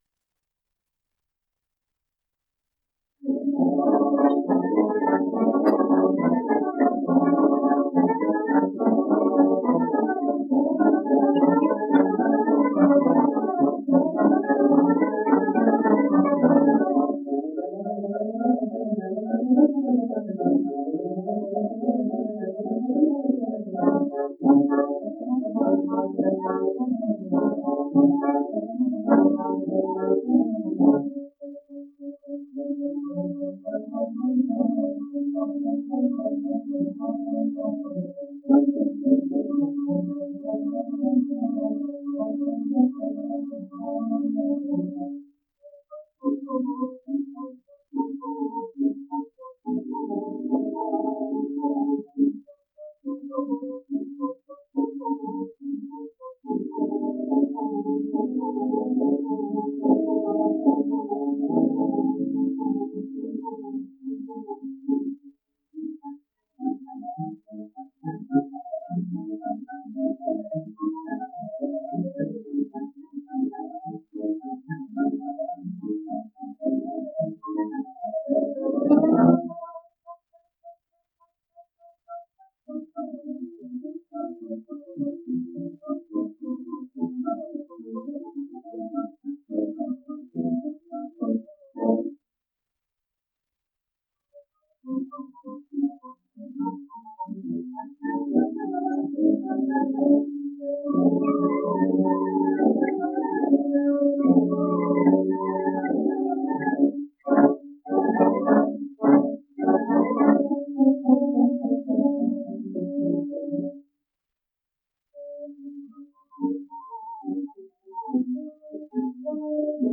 La boda de Luis Alonso: Intermedio (sonido remasterizado)